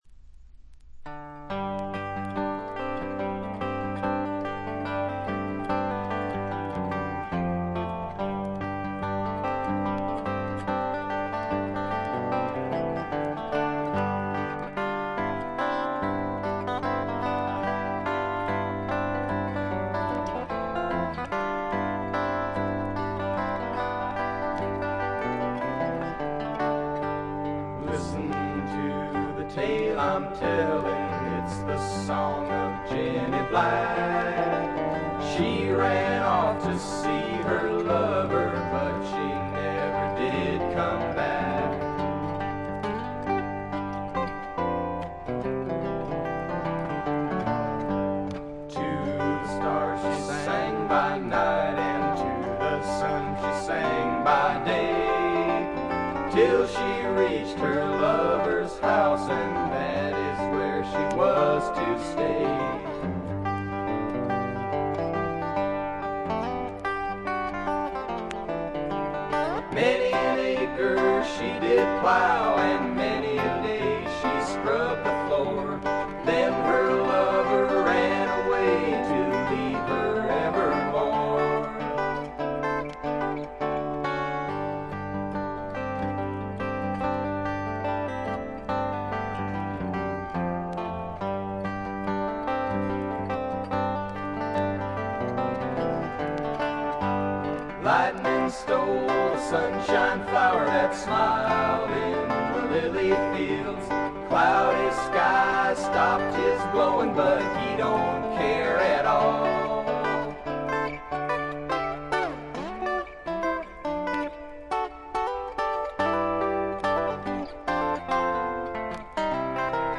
個人的にはジャケットの写真にあるようにフルアコ2台のエレクトリック・ギターの音が妙にツボに来ます。
試聴曲は現品からの取り込み音源です。
Guitar, Banjo, Vocals